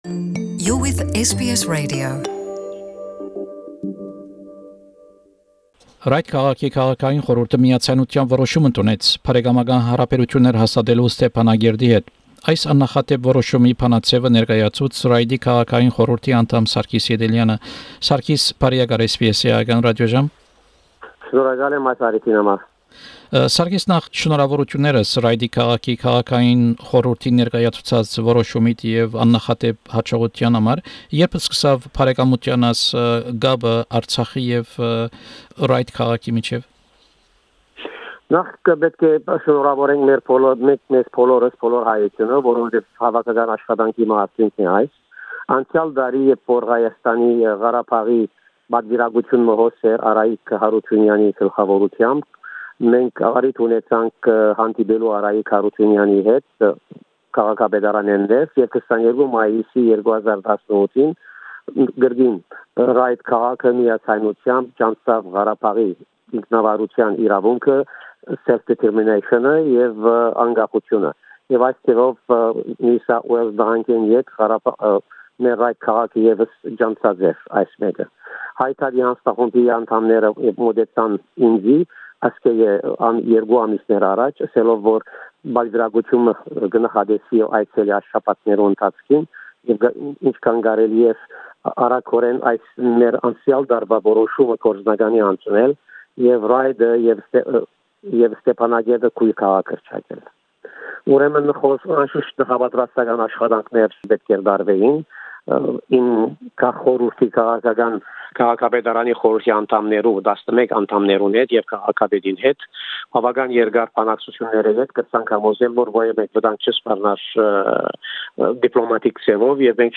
Հարցազրոյց Ռայտի քաղաքապետական խորհուրդի անդամ Պրն Սարգիս Ետելեանի հետ: Հարցազրոյցի նիւթն է Ռայտ քաղաքապետարանի բարեկամութեան որոշումը Ստեփանակերտի հետ: